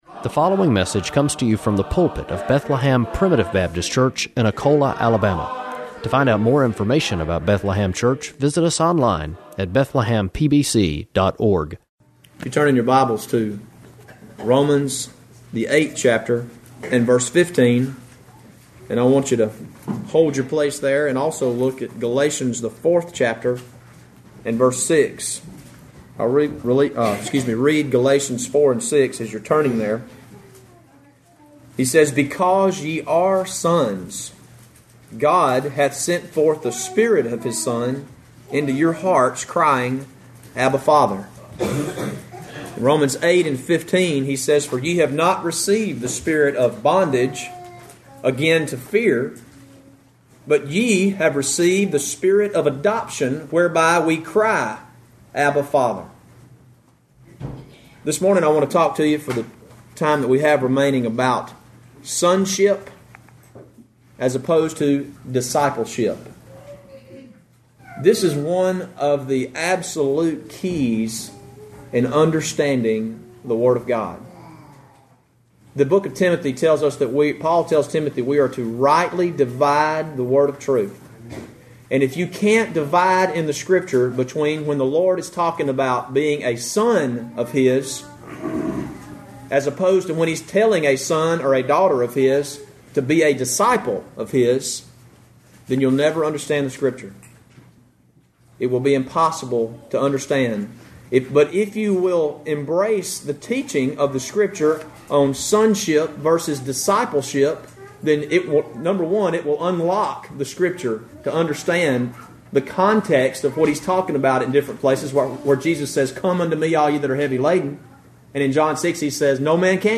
From our sermon archive http